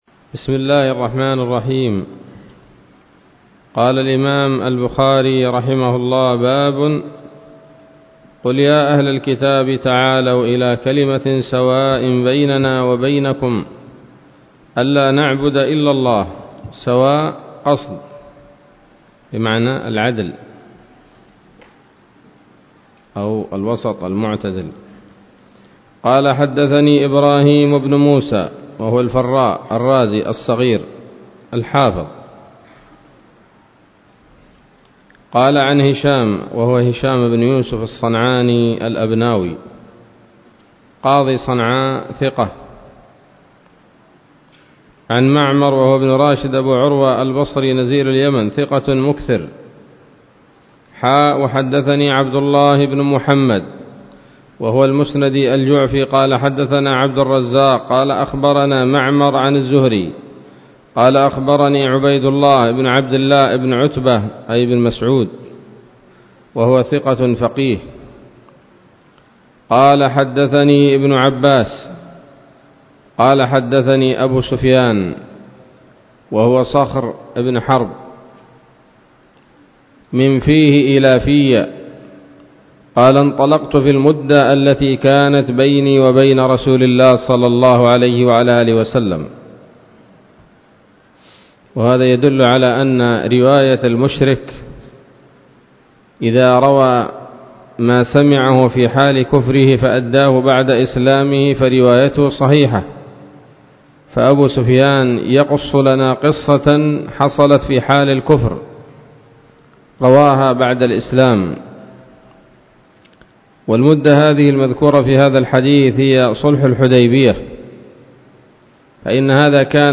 الدرس الثامن والأربعون من كتاب التفسير من صحيح الإمام البخاري